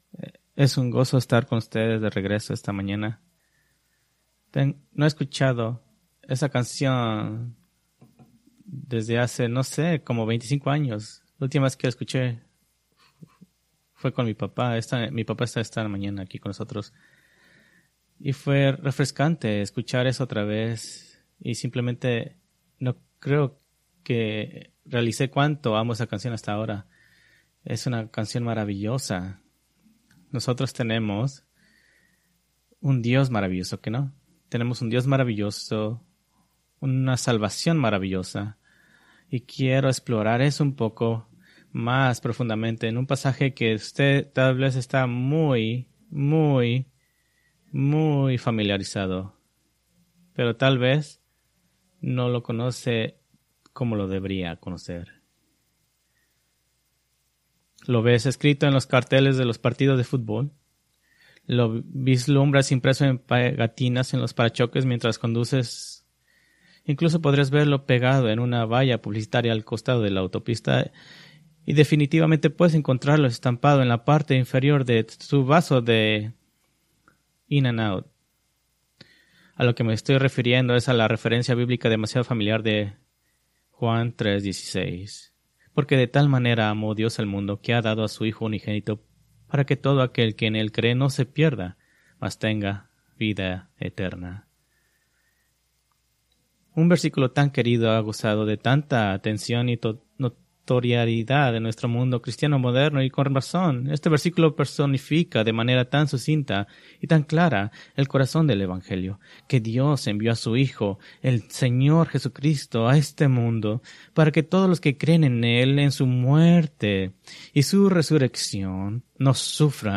Preached July 21, 2024 from Jeremías 29:11